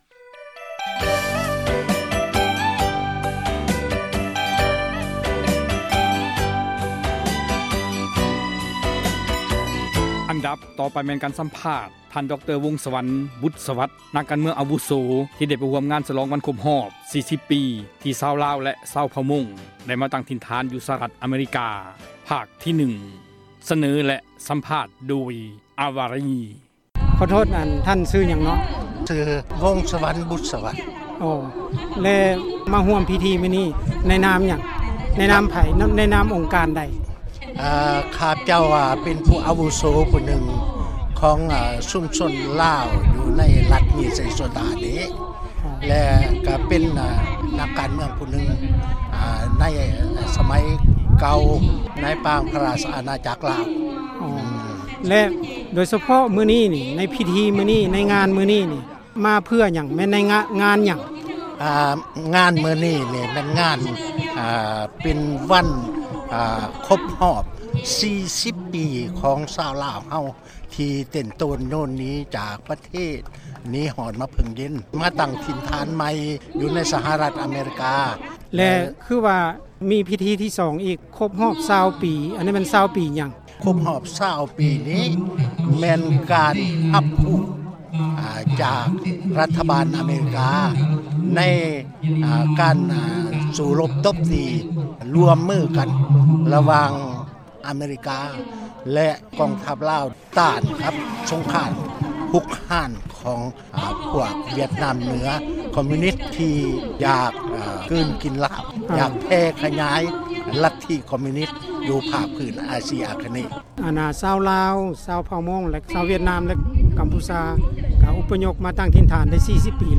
ການ ສຳພາດ ດຣ.ວົງສະຫວັນ ບຸດສະຫວັດ ນັກ ການເມືອງ ອາວຸໂສ ທີ່ ໄປຮ່ວມ ພິທີ ສວອງ ວັນ ຄົບຮອບ 40 ປີ ທີ່ ຊາວລາວ ຊາວເຜົ່າມົ້ງ ລາວ ໄດ້ມາຕັ້ງ ຖິ່ນຖານ ຢູ່ ສະຫະຣັຖ ອະເມຣິກາ.